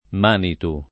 vai all'elenco alfabetico delle voci ingrandisci il carattere 100% rimpicciolisci il carattere stampa invia tramite posta elettronica codividi su Facebook manitù [ manit 2+ ] (meno com. manitu [ m # nitu ] e manito ) s. m. (etnol.)